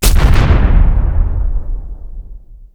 Player_Hit.wav